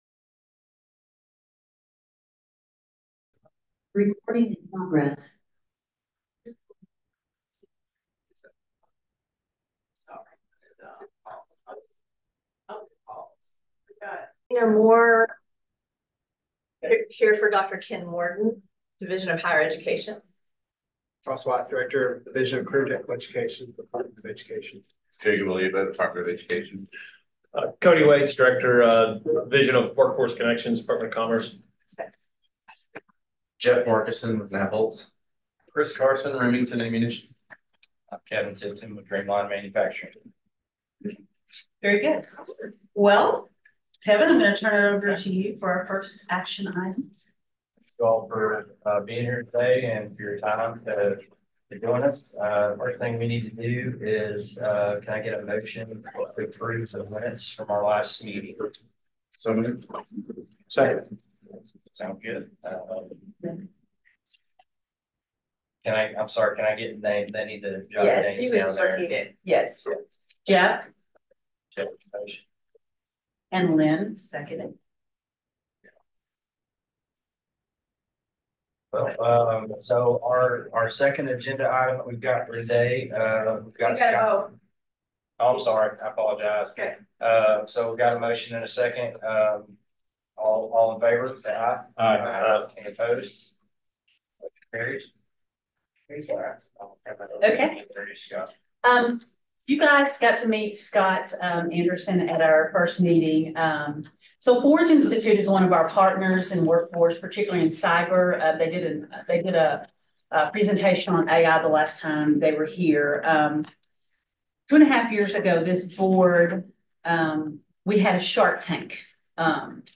All meetings of the Career Education and Workforce Development Board are recorded.
CEWD-Board-Meeting_Recording-June-2024.mp3